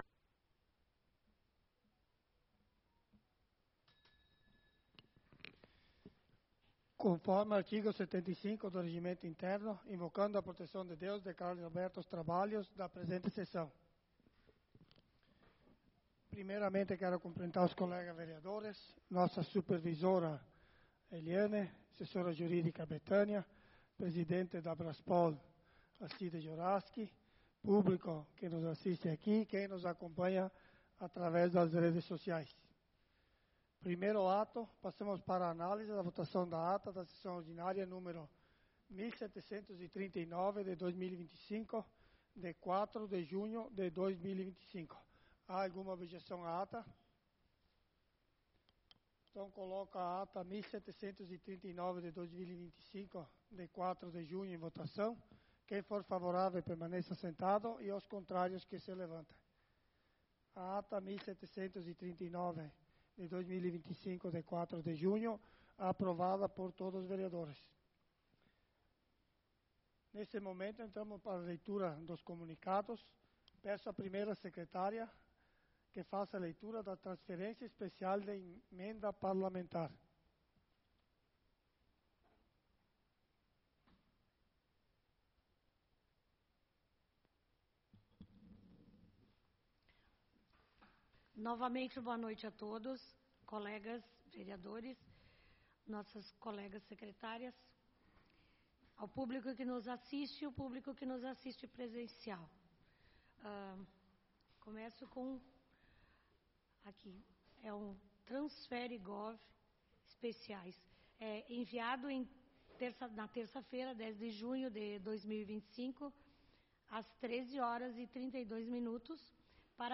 Sessão Ordinária do dia 11/06/2025